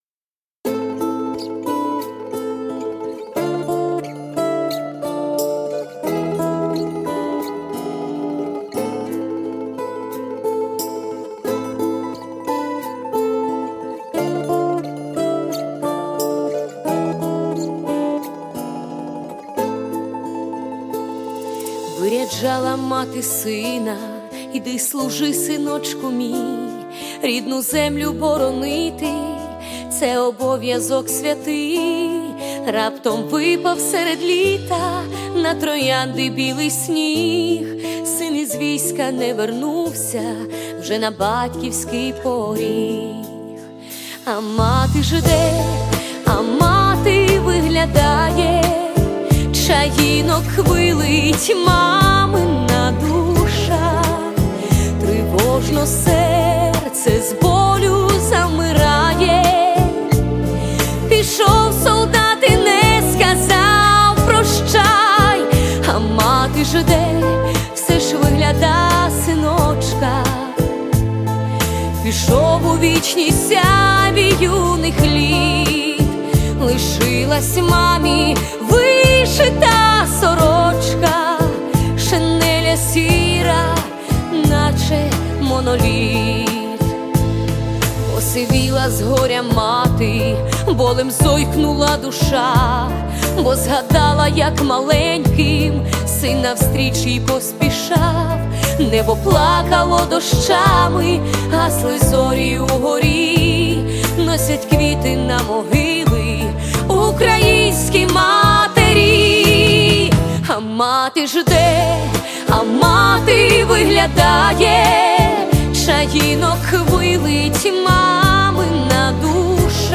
Всі мінусовки жанру Bossa-nova
Плюсовий запис